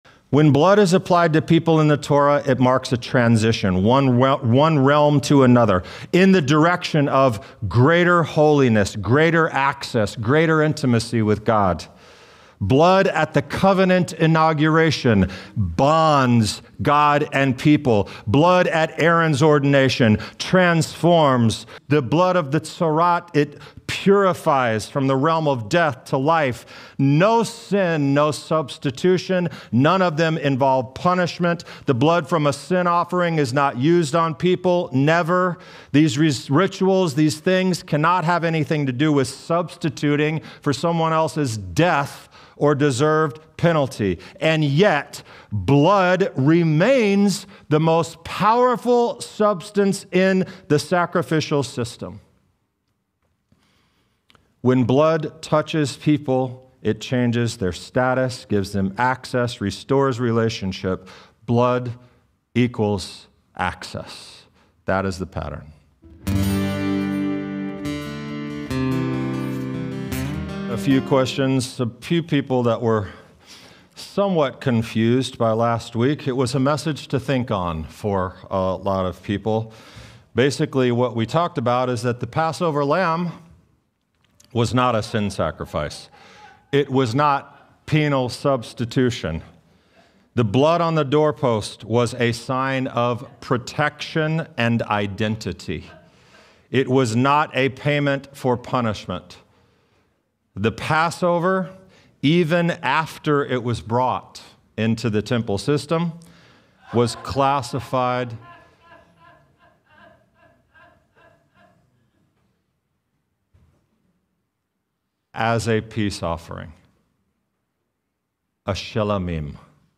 In this teaching, we begin peeling back the layers of assumption and rediscover the beauty, depth, and purpose of sacrifice in the Torah.